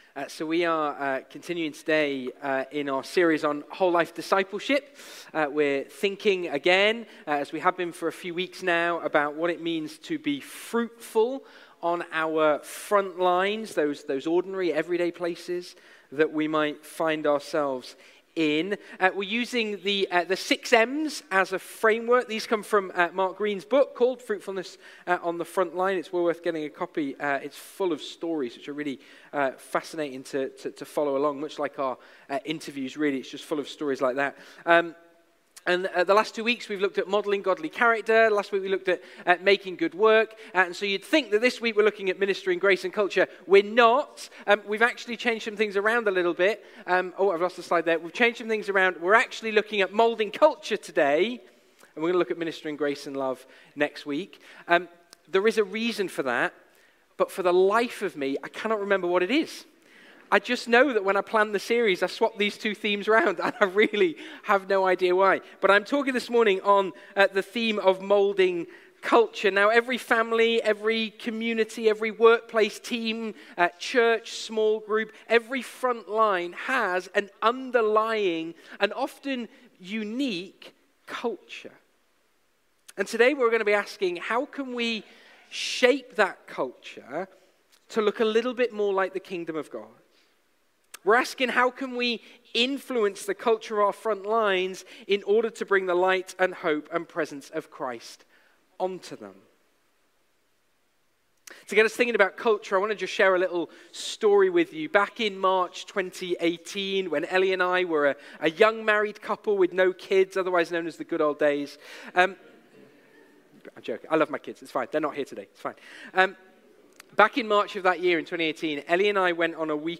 Passage: Matthew 5:1-16 Service Type: Sunday Morning